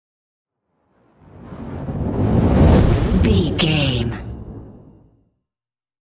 Whoosh to hit fire
Sound Effects
dark
intense
woosh to hit